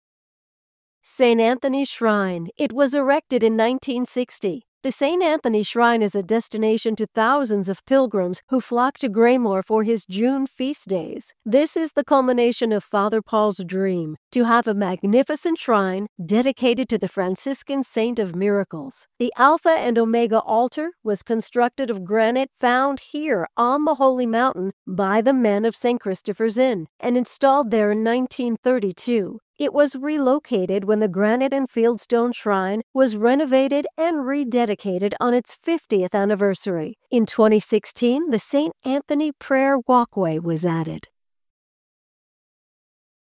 St-Anthony-Shrine-Tour.mp3